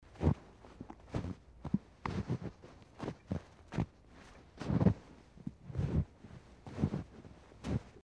Fondue, gelée ou épaisse, elle est tantôt molle, croustillante ou craquante. Lorsque la neige est poudreuse, les bruits de pas sont grandement atténués à la source. Les aigus sont filtrés et les basses subsistent, d’où ce craquement sourd caractéristique :
Snow-footsteps.mp3